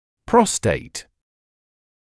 [‘prɔsteɪt][‘простэйт]предстательная железа, простата